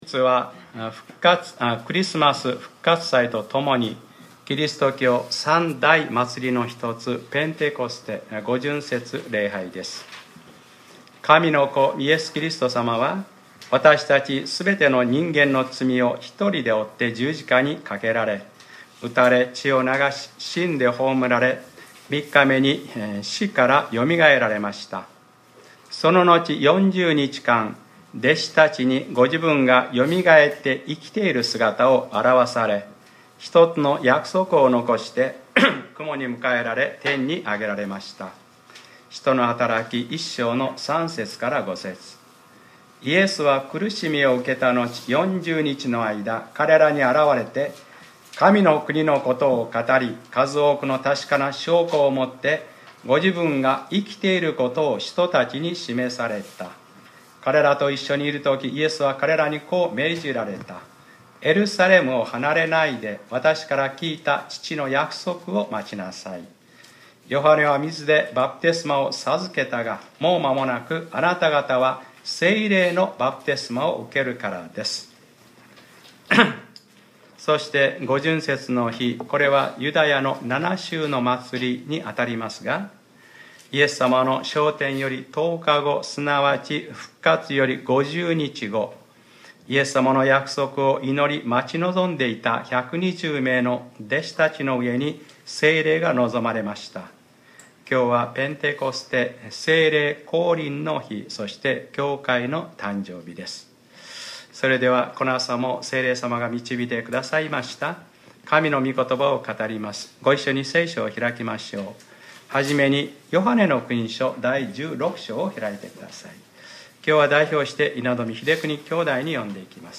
2015年5月24日（日）礼拝説教 『パラクレイトス(そばにいて助けて下さる方』